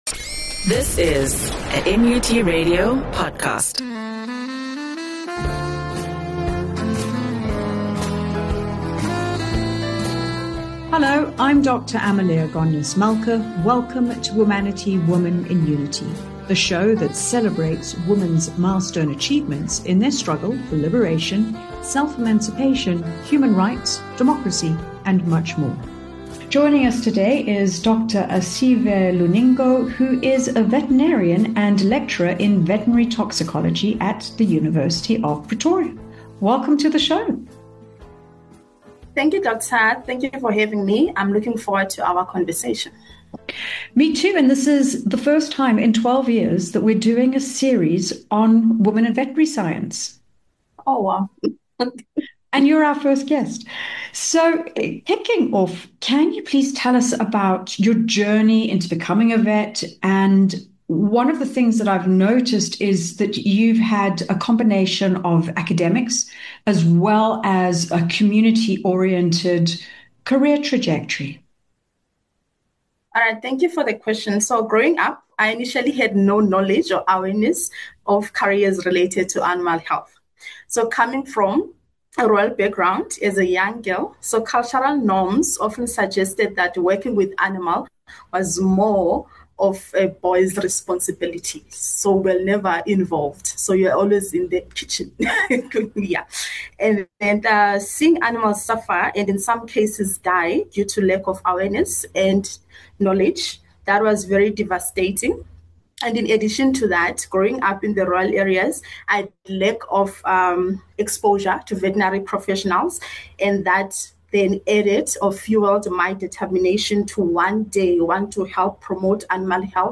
Her work highlights the growing impact of climate change on toxic plant proliferation and the urgent need for awareness, prevention, and research. The interview also confronts gender and cultural dynamics within veterinary science.